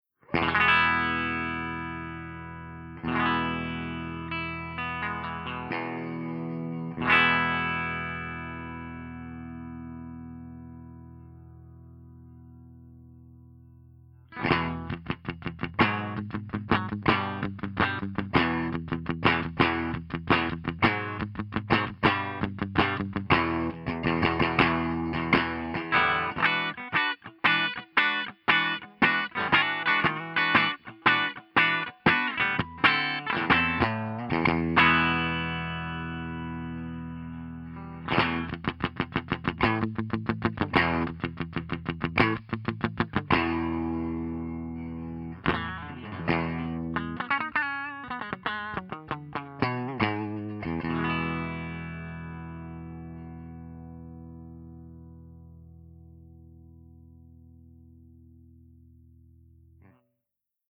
046_FENDER75_PUNCHYEQ_HB.mp3